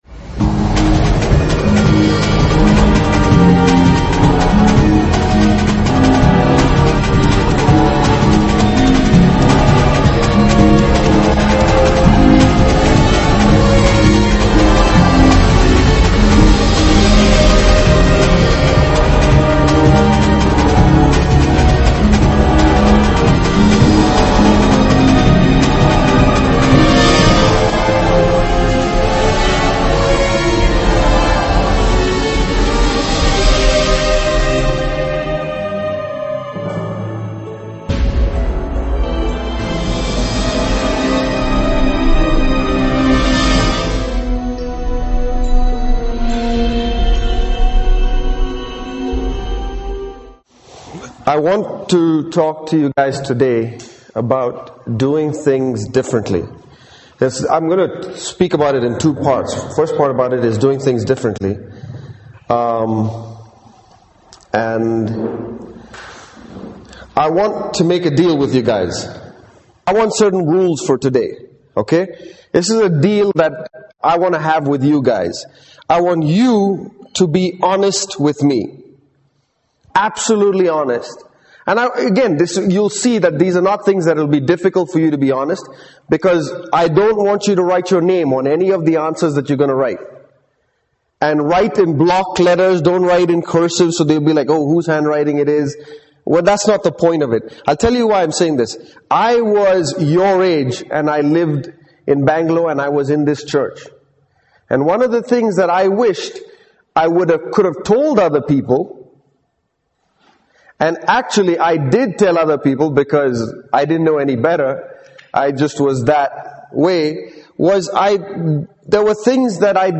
- 1 Cor 12:25 These messages were given at the CFC Youth Camp in December 2008 To view a message, click on the message title.